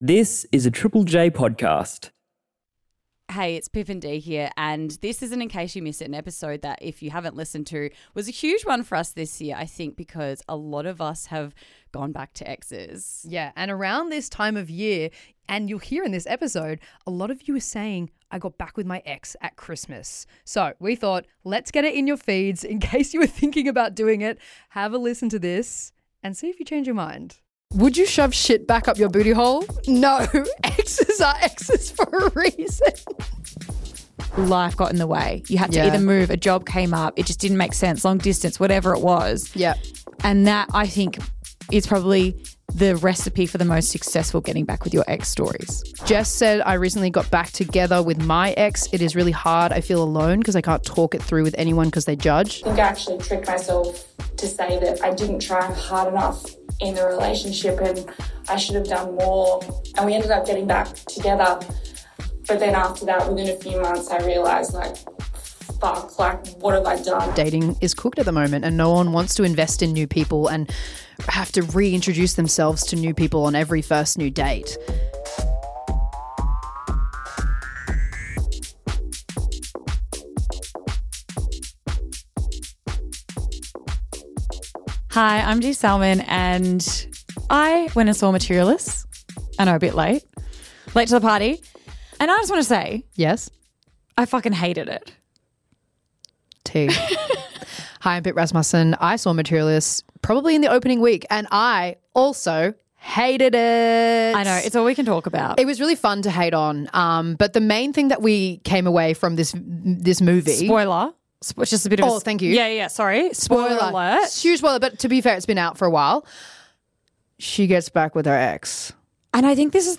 1 The myth of Pegasus | Sleep Story for Grown Ups | Greek Mythology Stories | Bedtime Stories 41:46